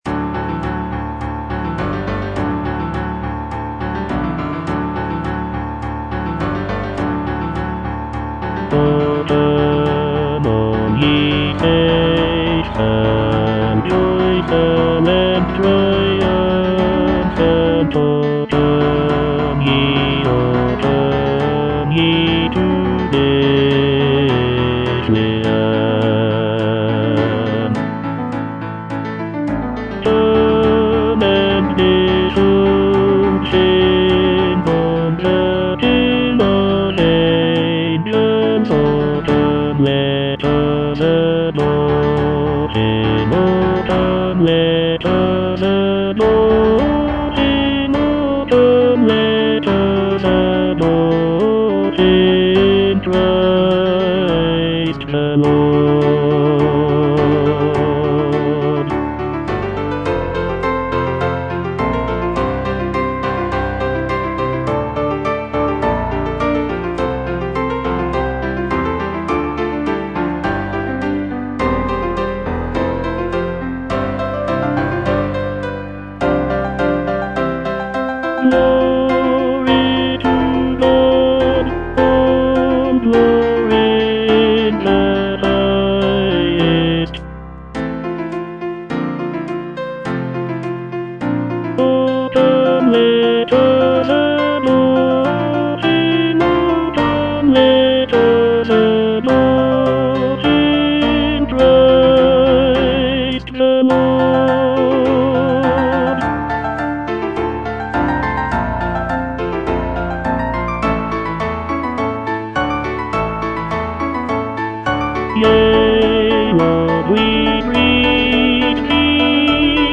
Tenor I (Voice with metronome)